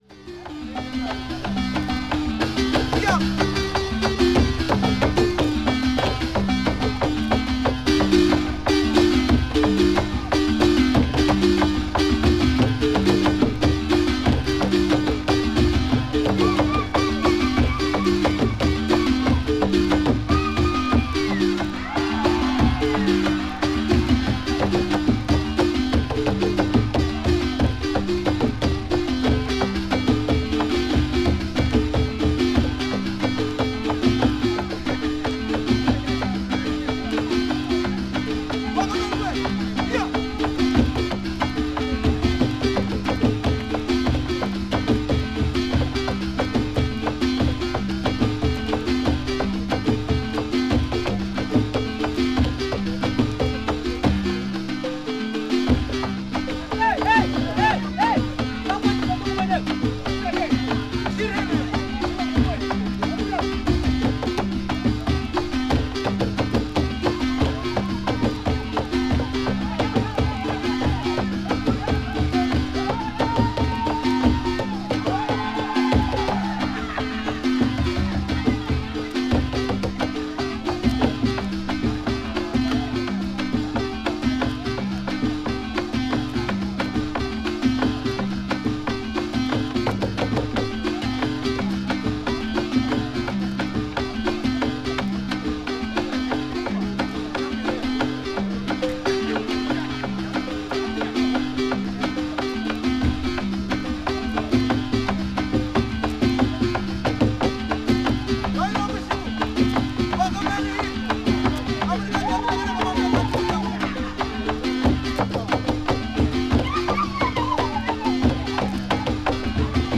Kutomboka Dance